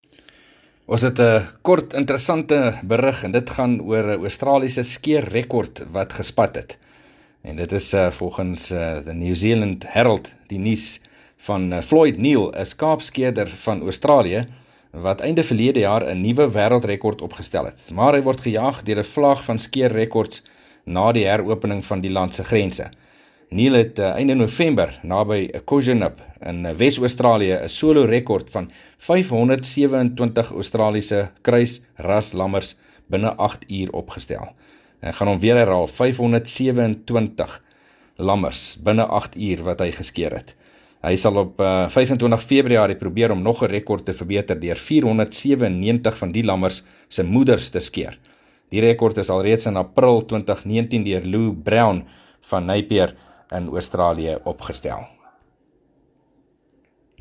15 Jan PM berig oor die wêreldrekord vir skaapskeer